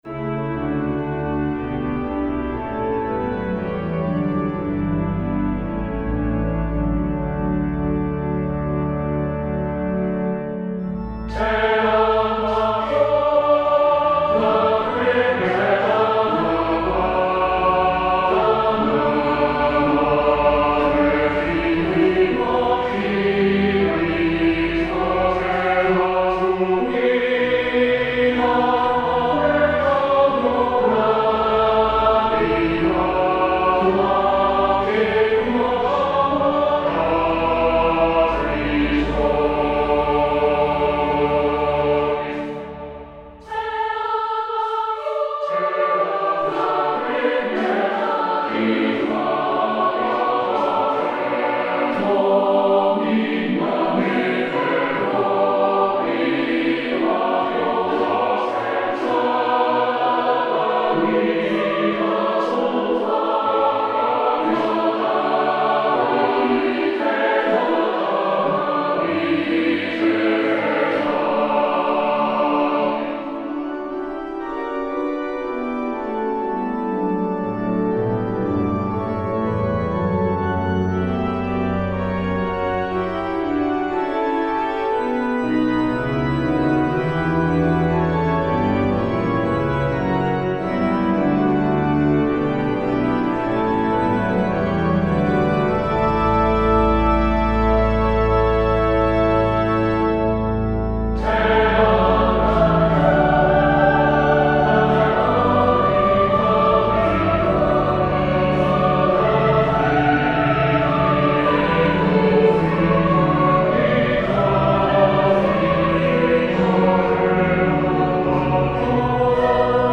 Descant to the hymn tune WOODLANDS - 'Tell out my soul the greatness of the Lord'